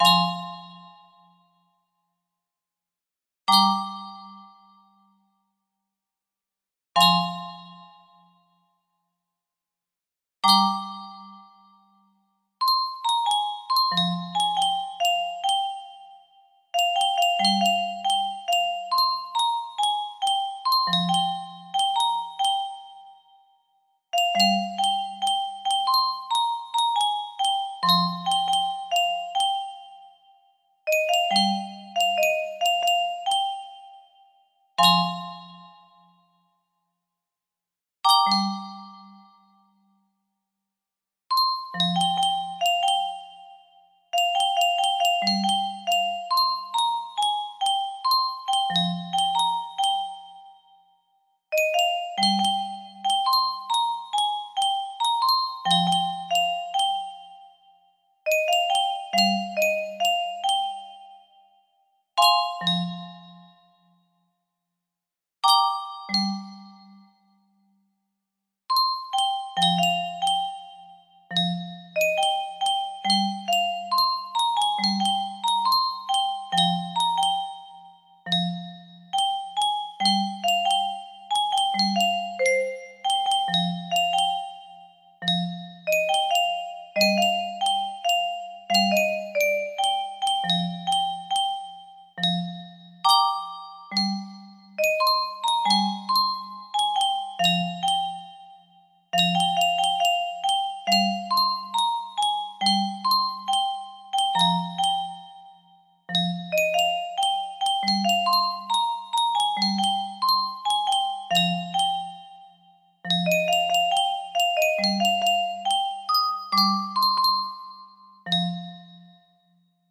Imported from MIDI File